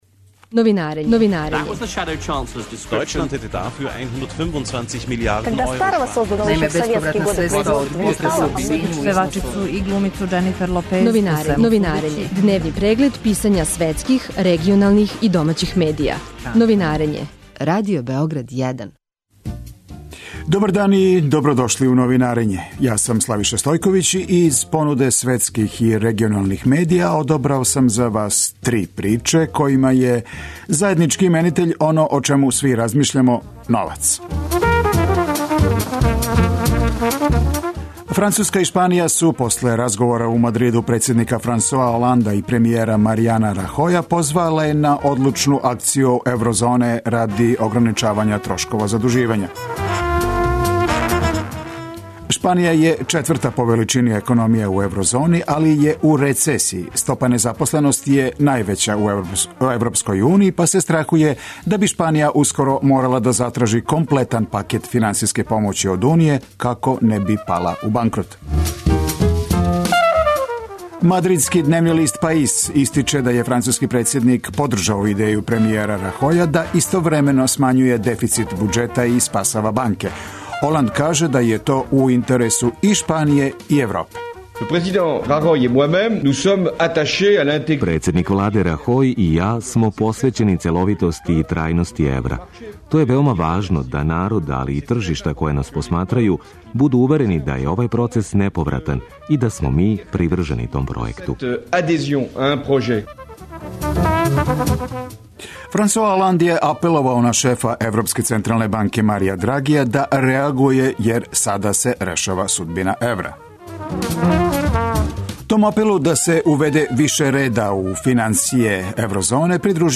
Преглед штампе у трајању од 15 минута. Чујте које приче су ударне овога јутра за највеће светске станице и листове, шта се догађа у региону и шта пише домаћа штампа.